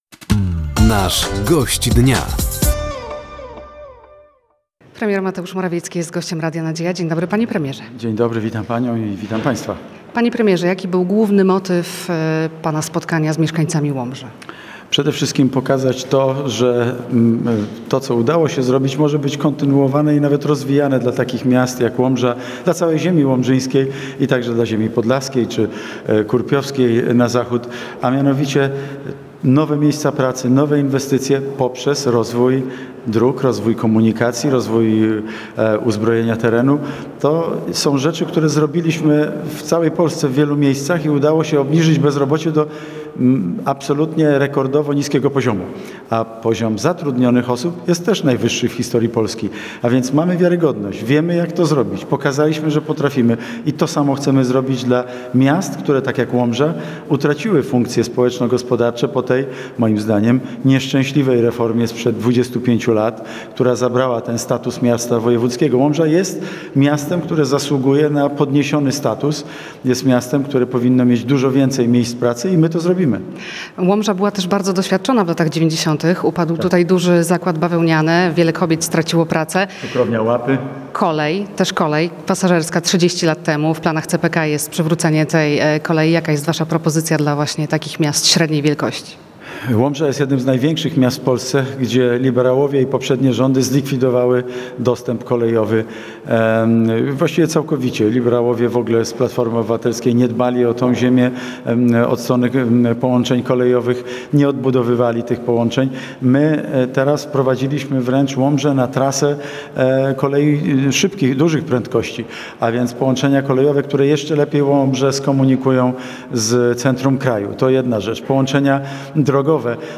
Gościem Dnia Radia Nadzieja był premier Mateusz Morawiecki. Tematem rozmowy było między innymi spotkanie z mieszkańcami Łomży i propozycja dla miast średniej wielkości. Szef rządu powiedział również o błędach popełnionych przez PiS w ostatnich latach.